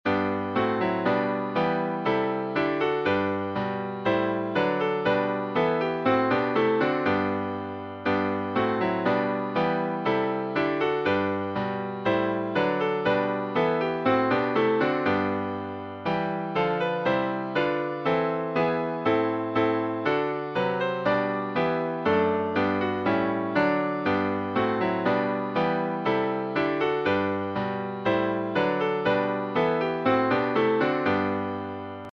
Music by: Trad. Dutch melody;